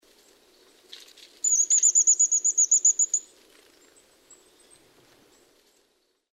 Ja näin viehättävältä kuulostaa, kun töyhtötiaisella on asiaa: Sii sii tsirririririt.
toyhtoitiainen_kutsu.mp3